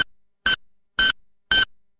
Digital Alarm Beep
A sharp, repeating digital alarm beep pattern like a morning clock or timer
digital-alarm-beep.mp3